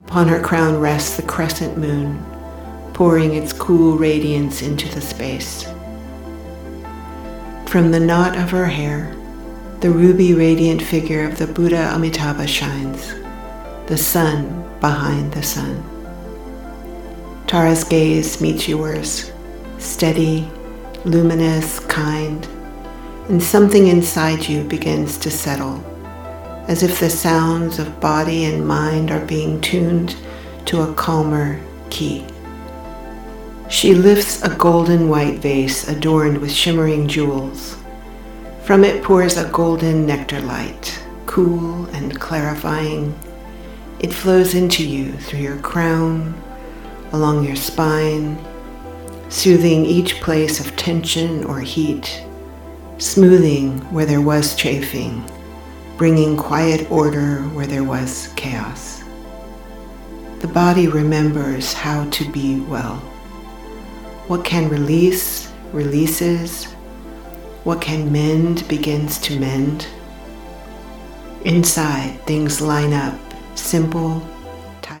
A 15-minute Golden Tara meditation for sensitive women – a gentle reset to cool agitation and restore ease.
In this gentle guided session, Golden Auspicious Tara invites your system to settle and realign with what’s already orderly and kind inside you.
It’s devotional in tone and respectful of lineage – you can simply receive it as imagery-based healing.